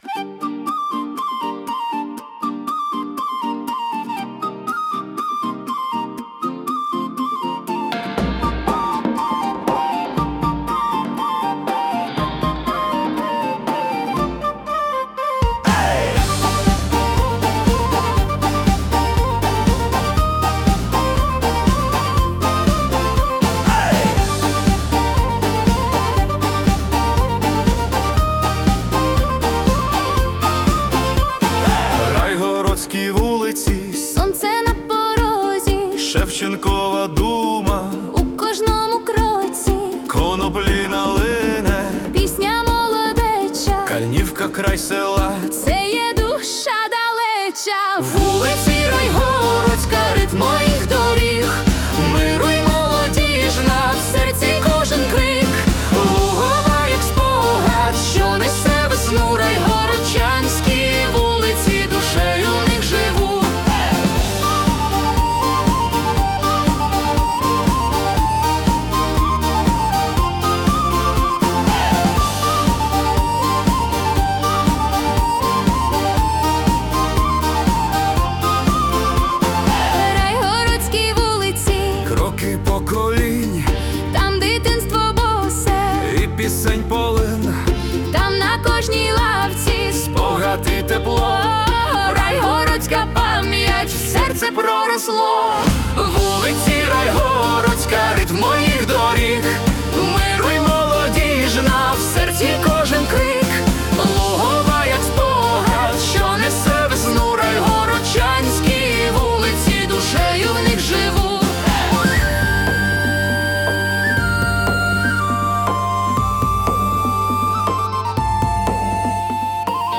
🎵 Жанр: Ukrainian Folk-Pop
це енергійна фолк-поп пісня (120 BPM)